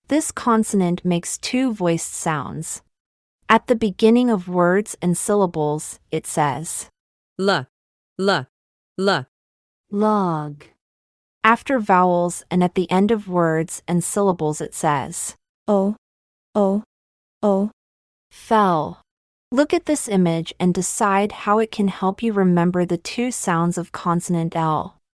At the beginning of words and syllables, it says: /L/, /L/, /L/, log.
After vowels and at the end of words and syllables the voiced consonant says: /əL/, /əL/, /əL/, fell.